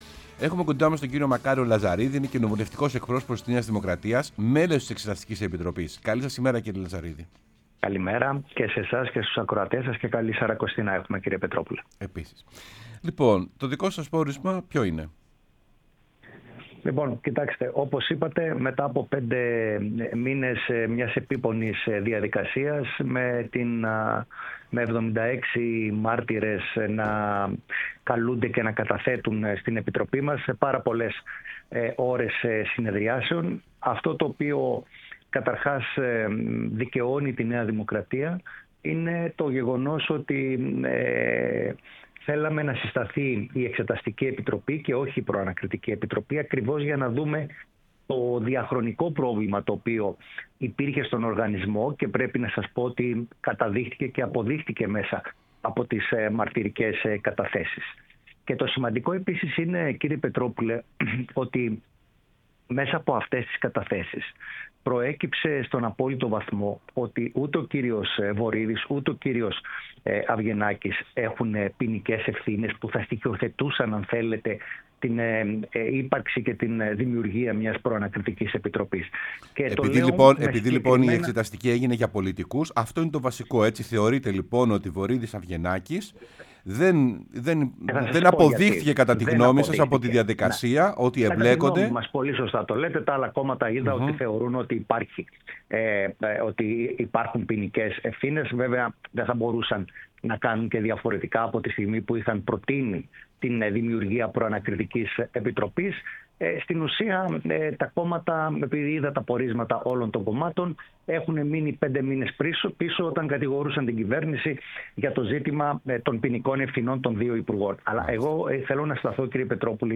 Μακάριος Λαζαρίδης, Κοινοβουλευτικός Εκπρόσωπος της ΝΔ και μέλος της Εξεταστικής Επιτροπής της Βουλής για τον ΟΠΕΚΕΠΕ μίλησε στην εκπομπή «Σεμνά και Ταπεινά»